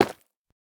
Minecraft Version Minecraft Version 1.21.5 Latest Release | Latest Snapshot 1.21.5 / assets / minecraft / sounds / block / nether_ore / break4.ogg Compare With Compare With Latest Release | Latest Snapshot
break4.ogg